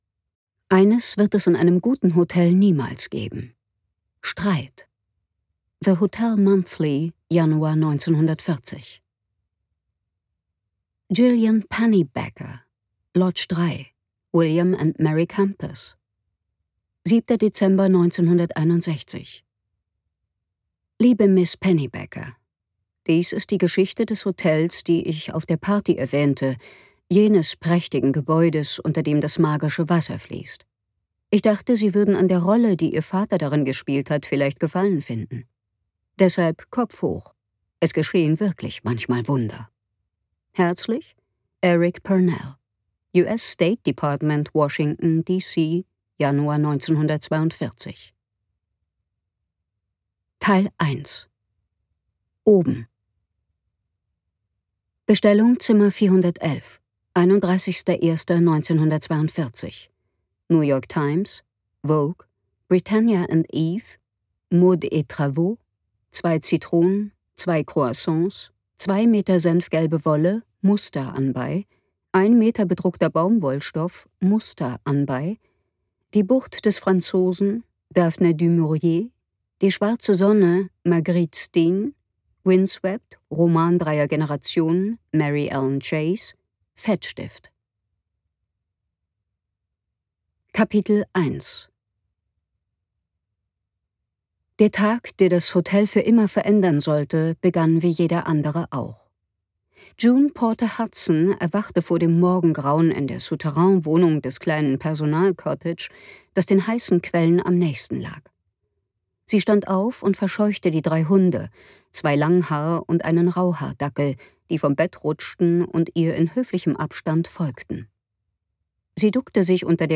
Grand Hotel Avalon - Maggie Stiefvater | argon hörbuch
Gekürzt Autorisierte, d.h. von Autor:innen und / oder Verlagen freigegebene, bearbeitete Fassung.